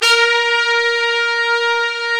BRS TPTS 0HR.wav